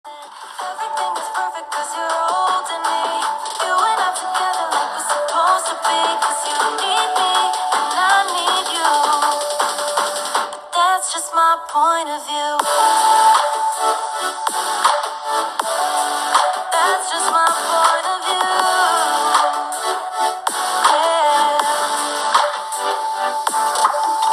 ▼OnePlus Nord CE 5Gのモノラルスピーカーの音はこちら！
ただ、ステレオスピーカーのような迫力ある音の広がりを体験できません。